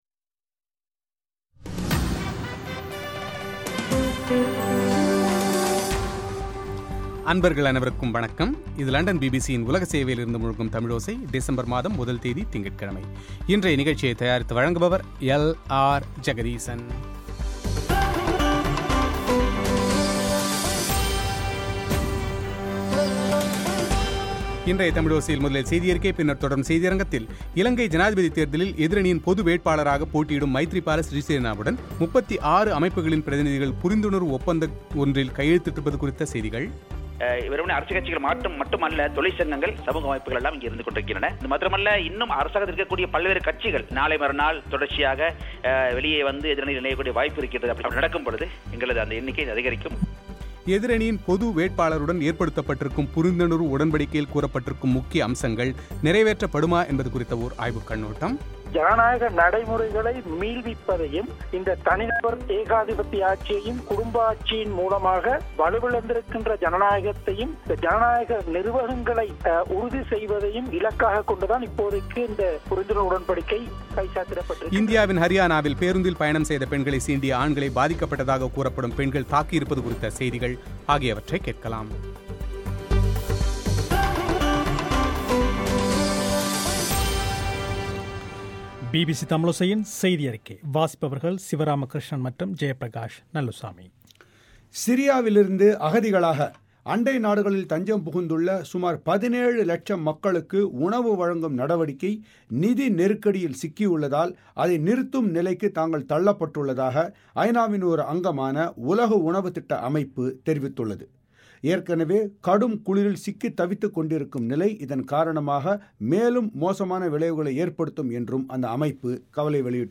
இலங்கை ஜனாதிபதித் தேர்தலில் எதிரணியின் பொது வேட்பாளராக போட்டியிடும் மைத்திரிபால சிறிசேனவுடன் 36 அமைப்புகளின் பிரதிநிதிகள் புரிந்துணர்வு உடன்படிக்கையில் கையெழுத்திட்டிருப்பது குறித்து இந்த உடன்படிக்கையில் கைச்சாத்திட்டுள்ள ஜனநாயக மக்கள் முன்னணியின் தலைவர் மனோ கணேசனின் பேட்டி;